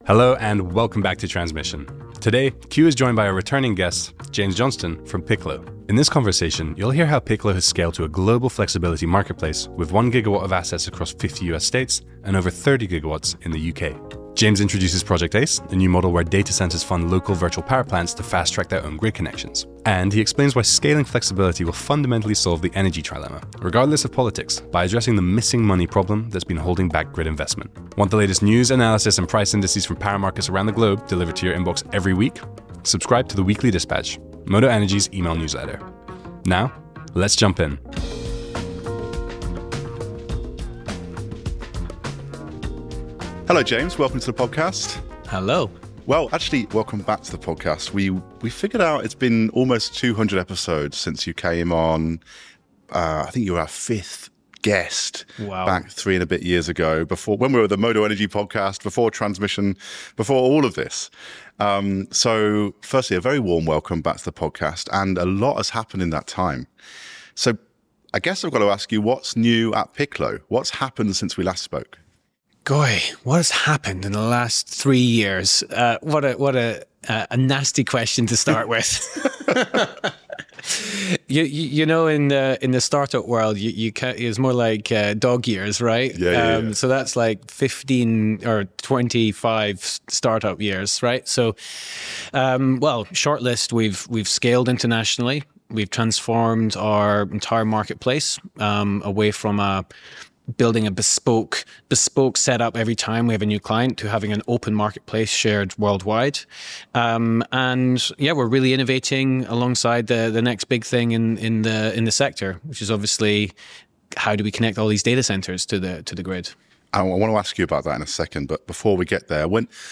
All of our interviews are available to watch or listen to on the Modo Energy site.